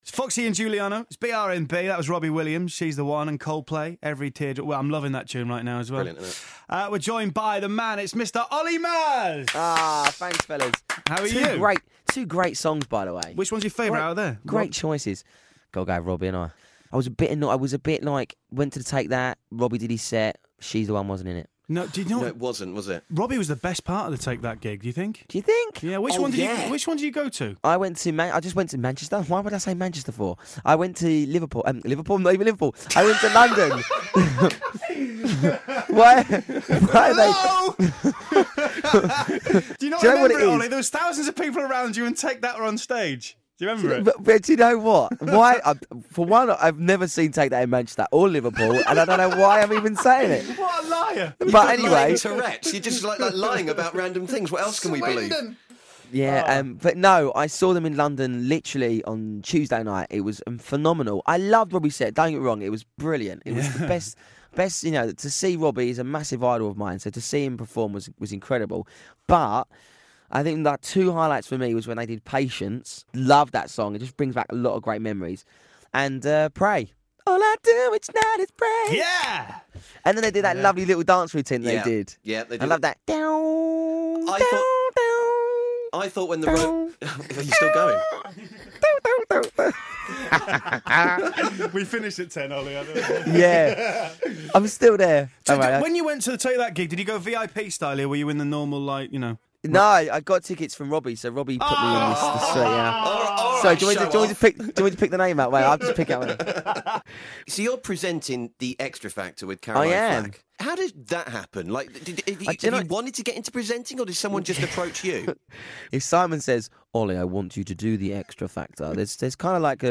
Olly Murs Interview Part One